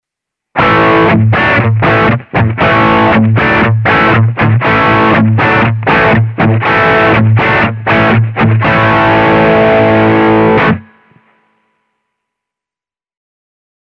Thanks to its twin coils a humbucker generally sounds bigger and fatter than a singlecoil, which is great for achieving overdriven and distorted tones.
This is what a typical humbucker sounds like:
Hamer drive
hamer-drive.mp3